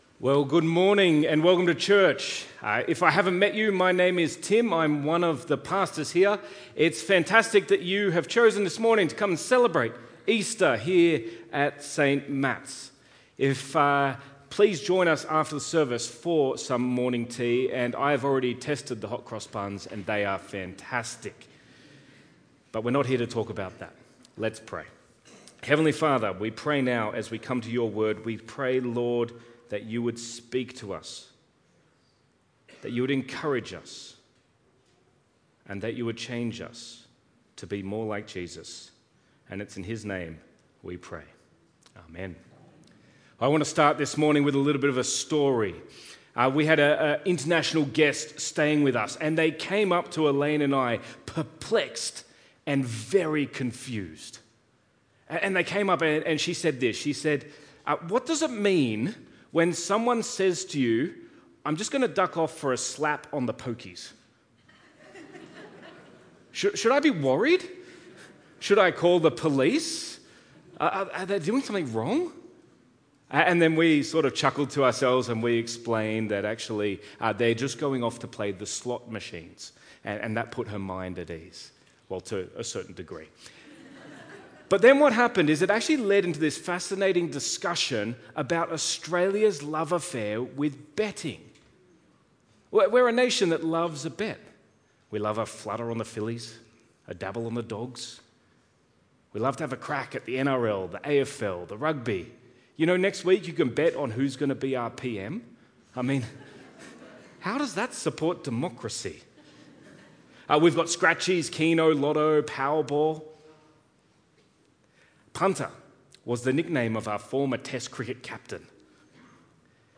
Sermon Series | St Matthew's Wanniassa